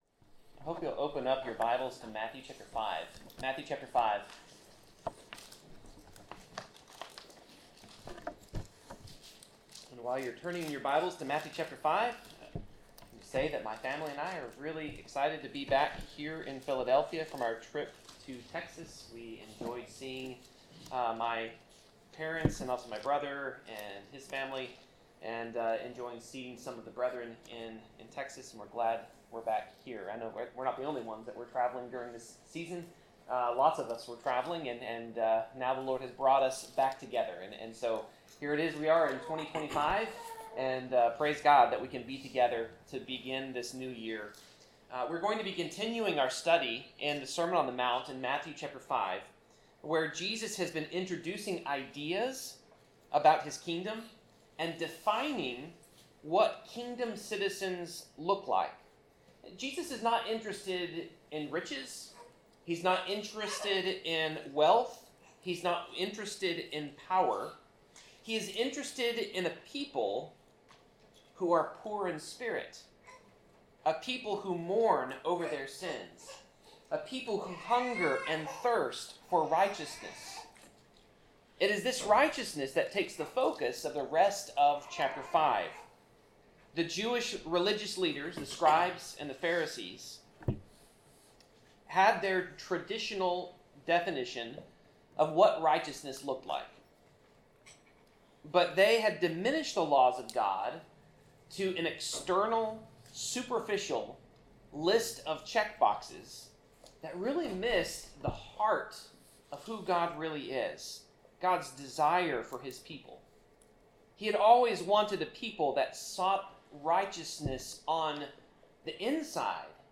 Passage: Matthew 5:33-48 Service Type: Sermon